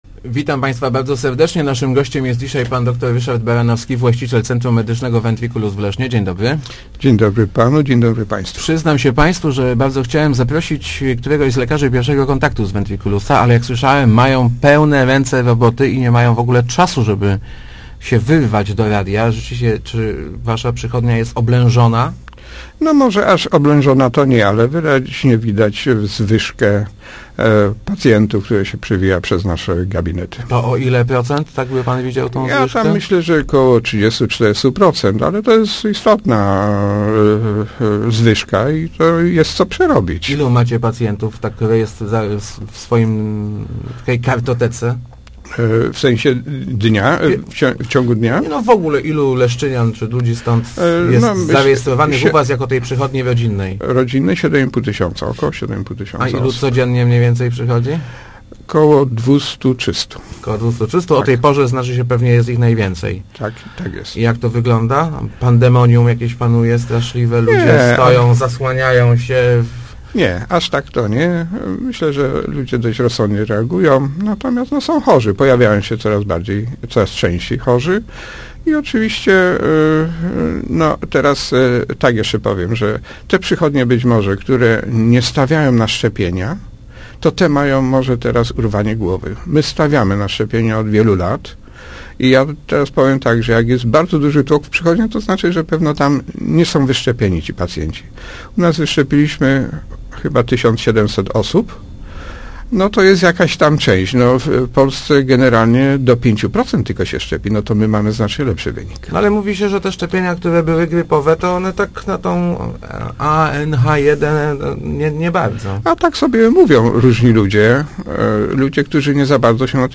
1020rozmowaleszno.mp3